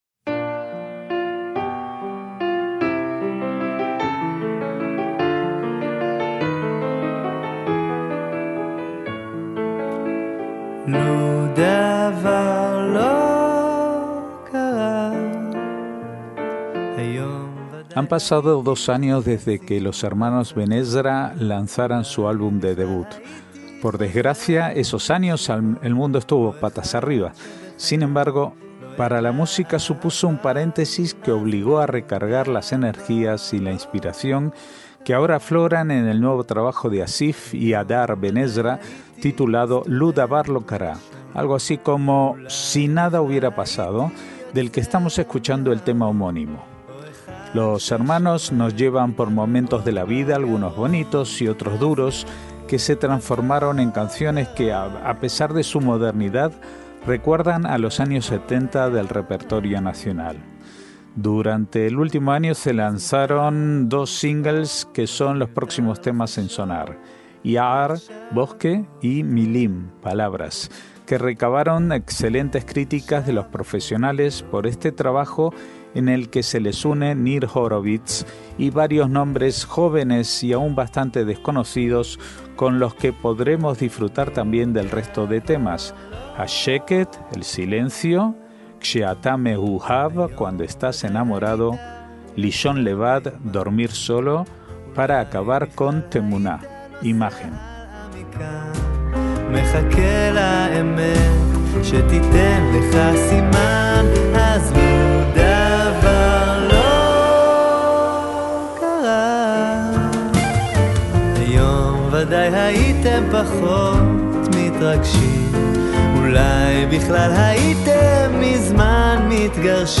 indie pop hebreo
MÚSICA ISRAELÍ